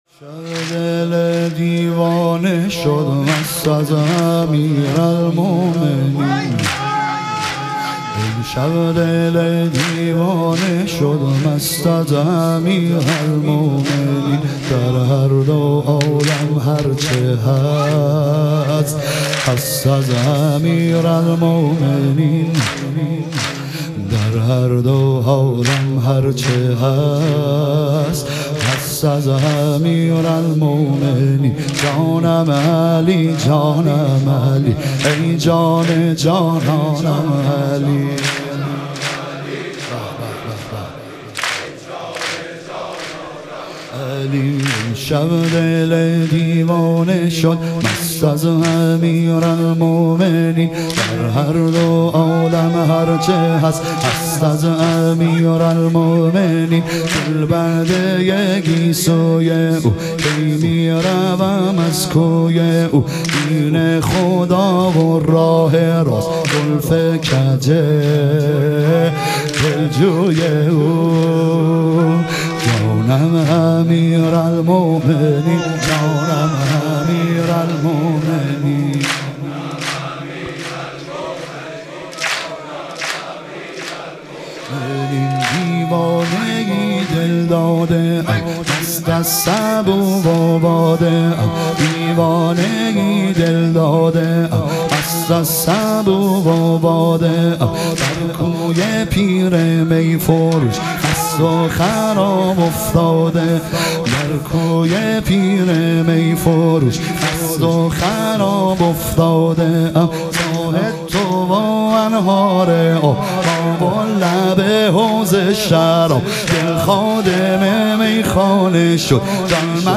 لیالی قدر و شهادت امیرالمومنین علیه السلام - واحد